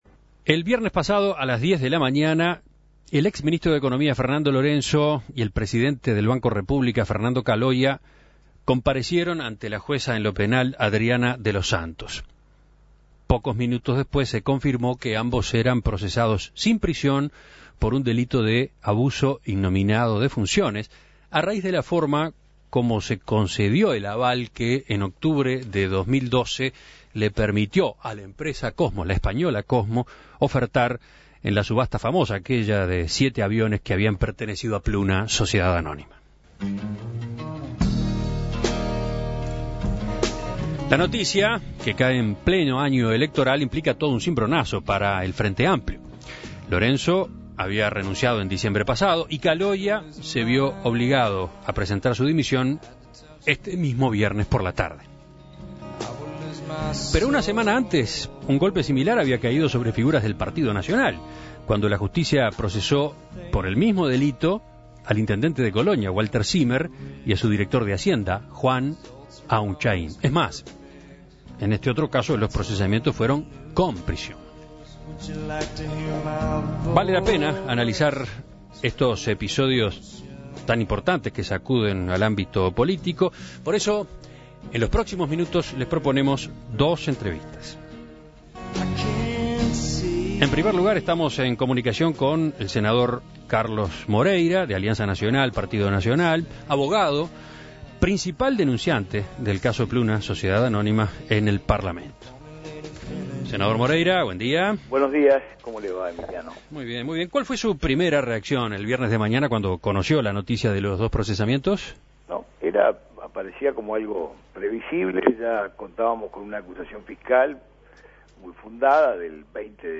El senador Carlos Moreira fue el principal denunciante del caso Pluna en el Parlamento, por este motivo En Perspectiva lo consultó para saber sus primeras reacciones.